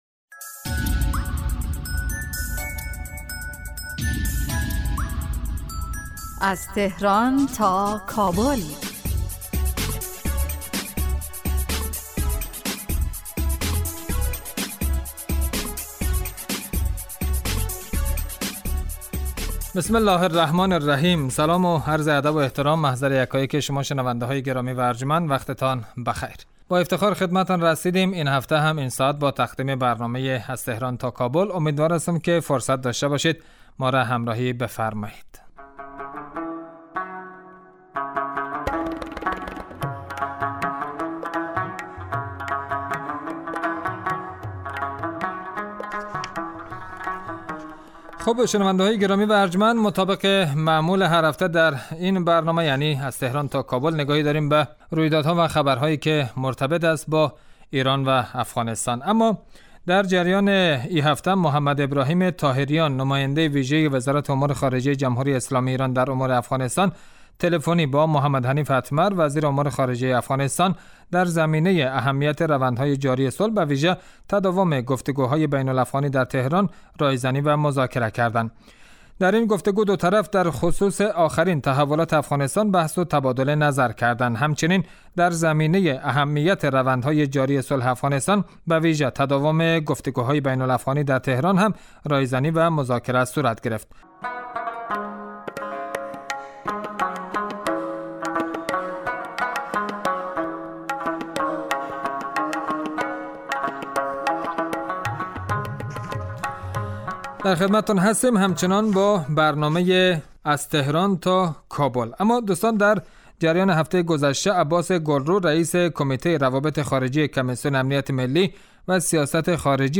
گفت و گوی تلفنی محمد ابراهیم طاهریان با محمد حنیف اتمر پیرامون اهمیت روندهای جاری صلح به ویژه تداوم گفت و گوهای بین الافغانی در تهران.